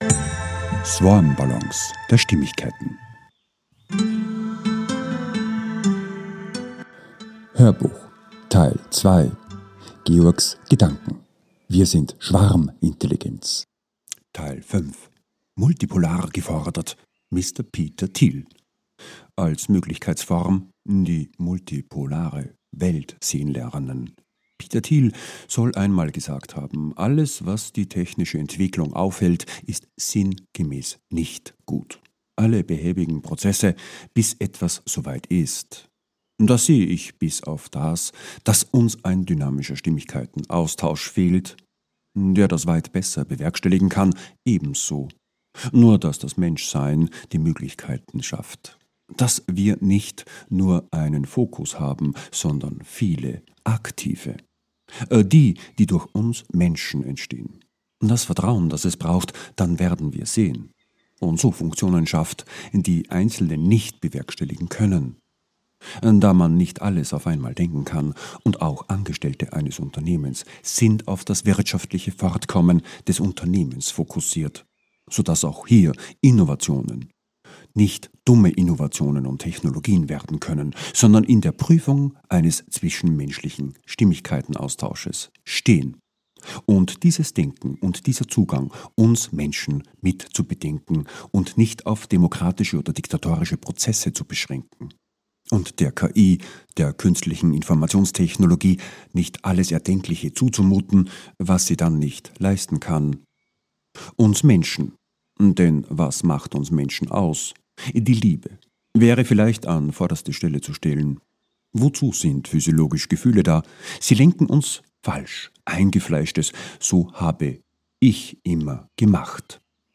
HÖRBUCH TEIL 2 - 05 - WIR SIND SCHWARMINTELLIGENZ 2 - MULTIPOLAR - Mr. Peter THIEL ~ SwarmBallons A-Z der Stimmigkeit Podcast